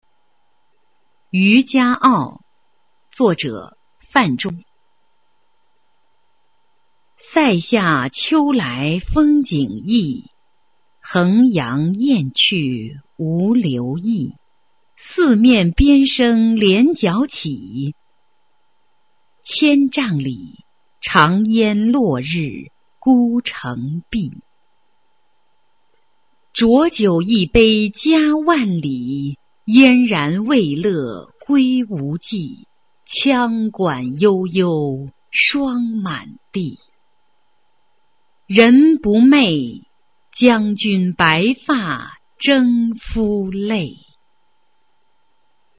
范仲淹《渔家傲·塞下秋风景异》原文和译文（含mp3朗读）